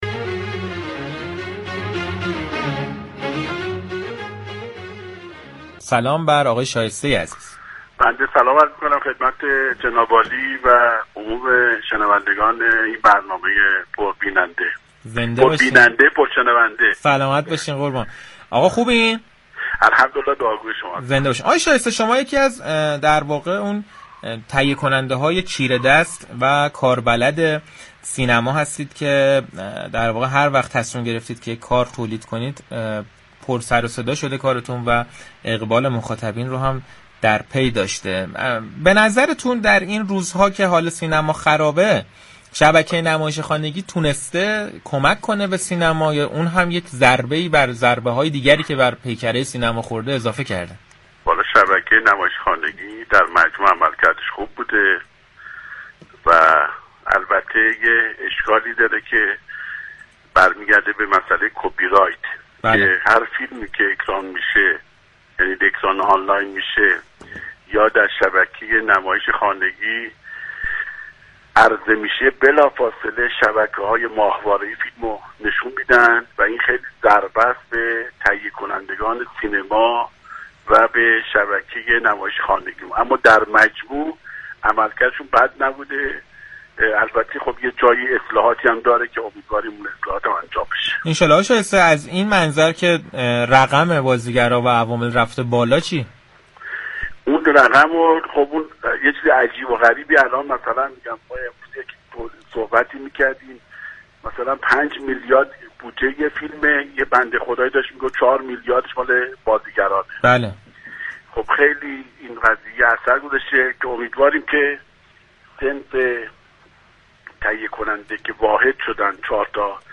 در گفتگو با برنامه صحنه رادیو تهران